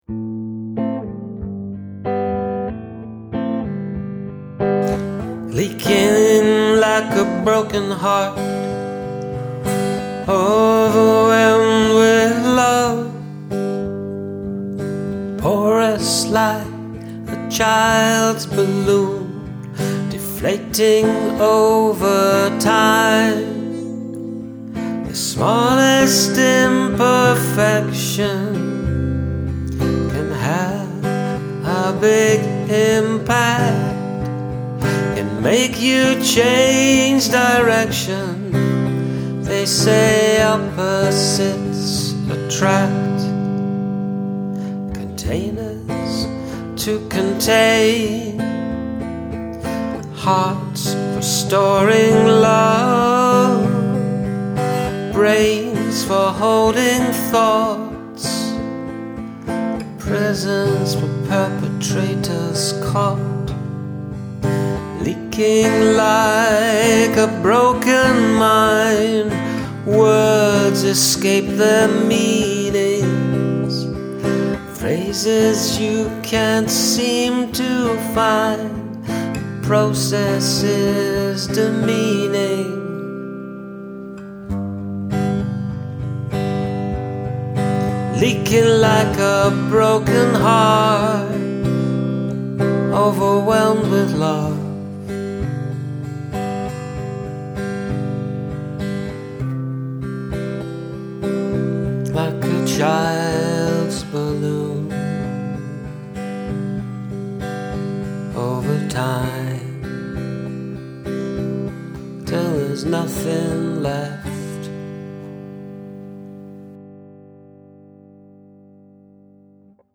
Really great groove choice for the lyrics.
I really like the verse about the different kinds of containers and the rhythmic guitar in the verses.
A lovely guitar sound, almost like you've double-tracked it.